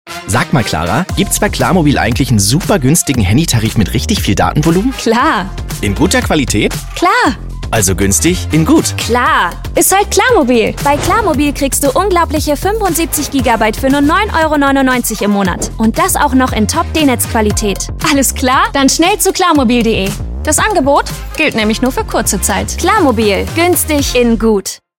dunkel, sonor, souverän, markant, plakativ, sehr variabel
Mittel minus (25-45)
Commercial (Werbung)